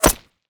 bullet_impact_rock_02.wav